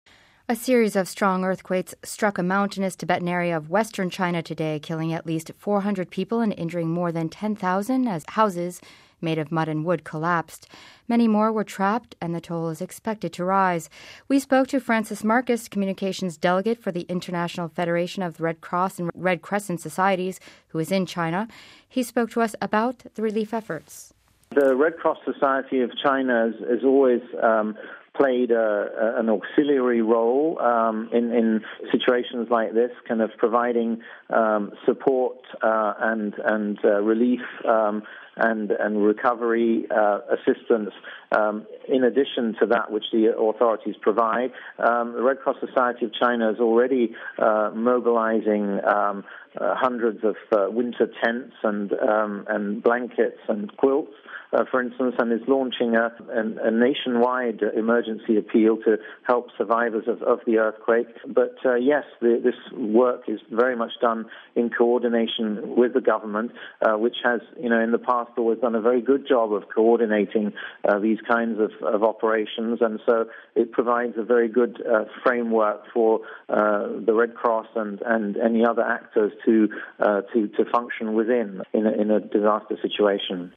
He told us more about the relief efforts.